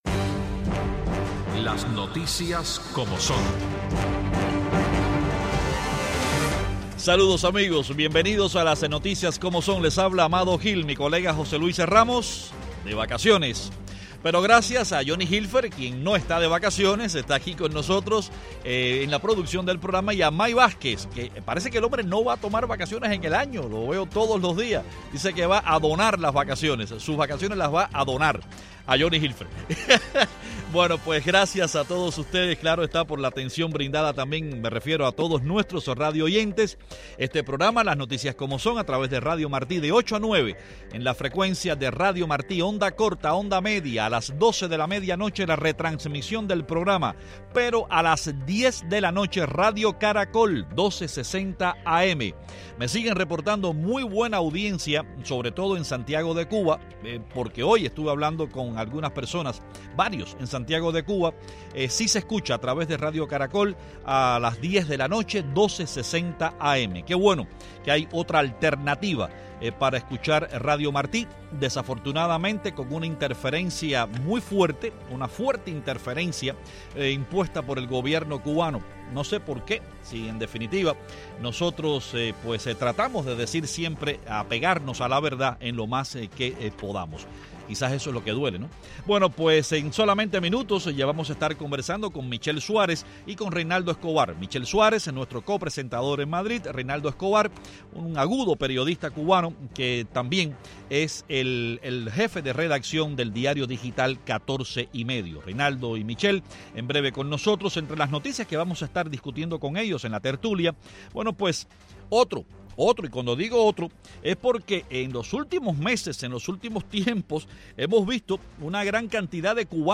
Hoy, desde Varsovia, hablamos con los abogados independientes cubanos